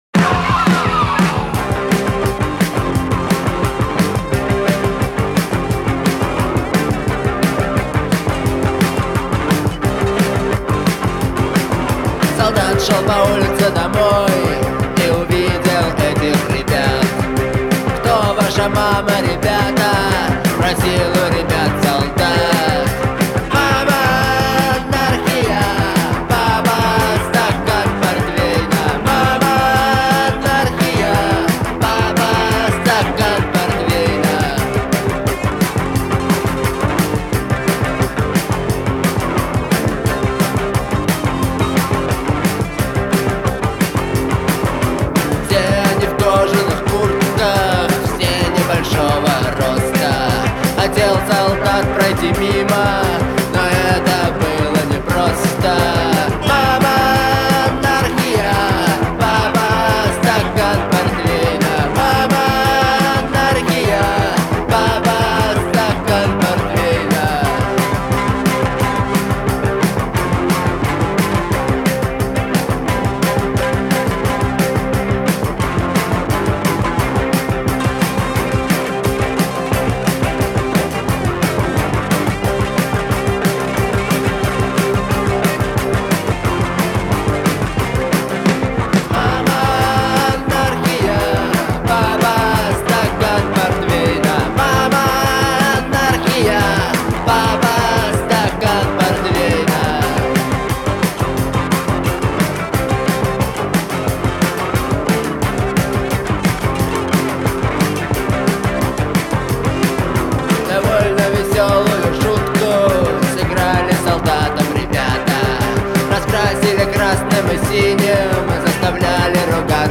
выполненная в жанре рок.